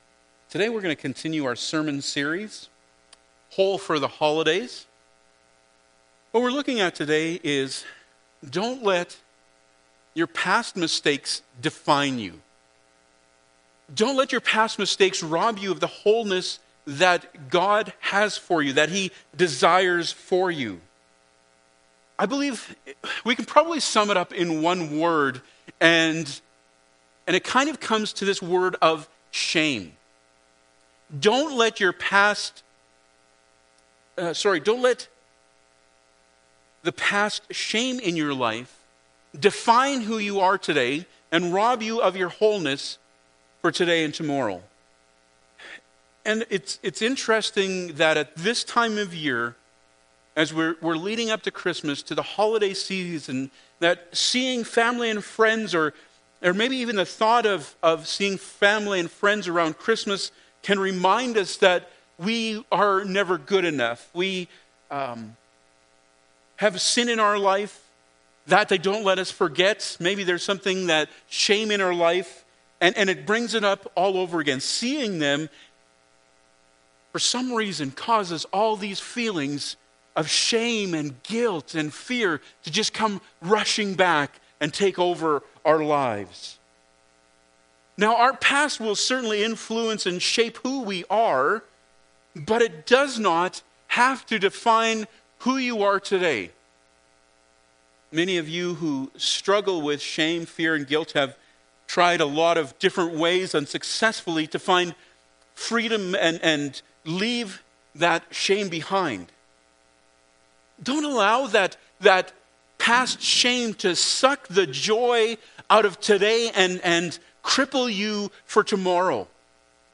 Col 2:13-14 Service Type: Sunday Morning Bible Text